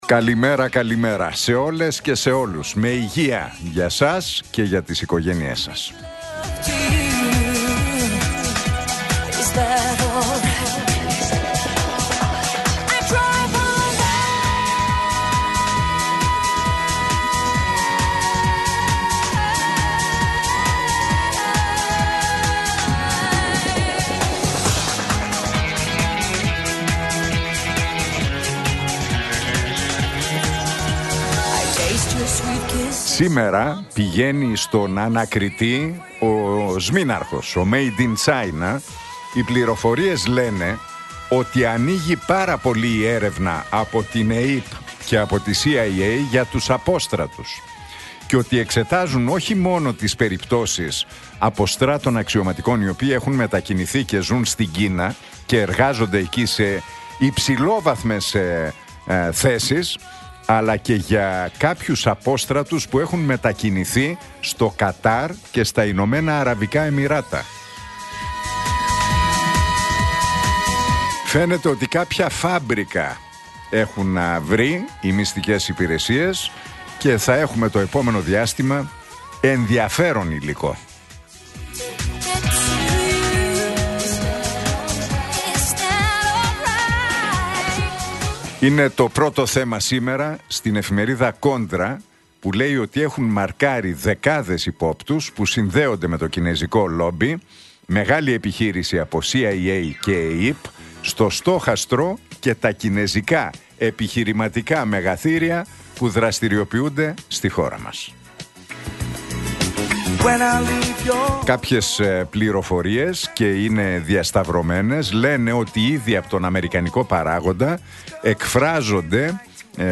Ακούστε το σχόλιο του Νίκου Χατζηνικολάου στον ραδιοφωνικό σταθμό Realfm 97,8, την Τρίτη 10 Φεβρουαρίου 2026.